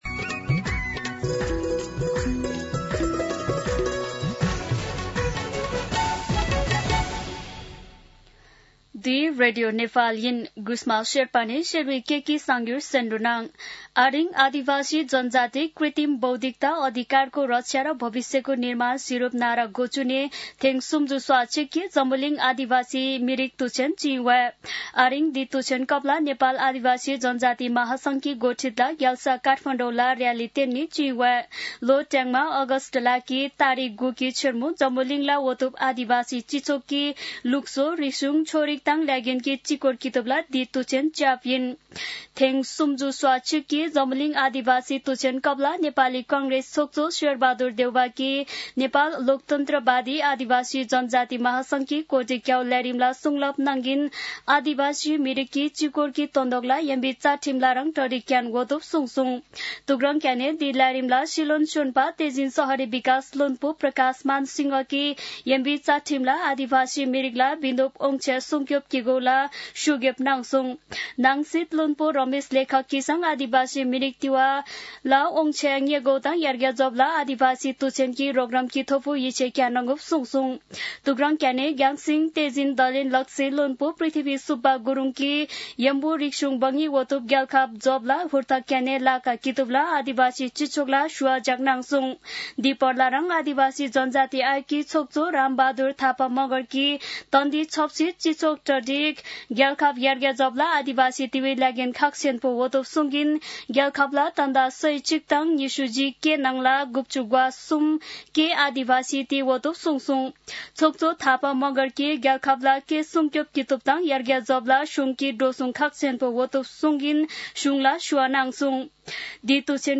शेर्पा भाषाको समाचार : २४ साउन , २०८२
Sherpa-News-24.mp3